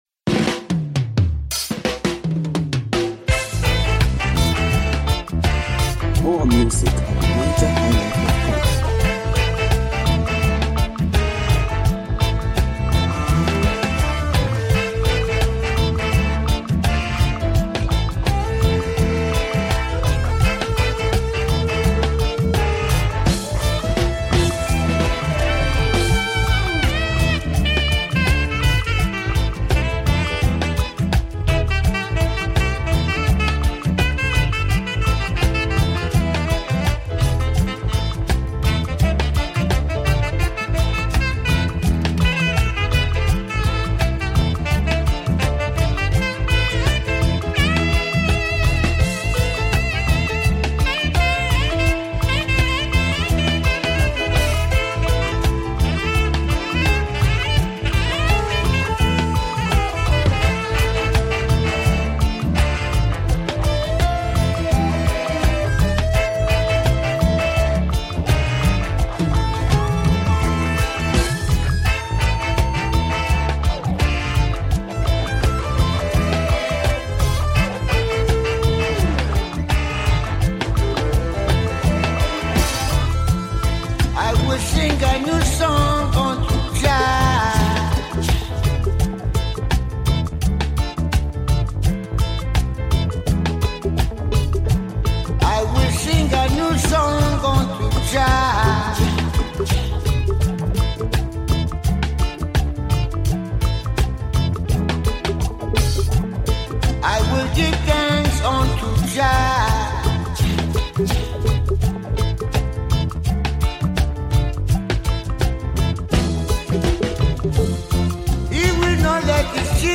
Home » Ragae
Nigerian Reggae Music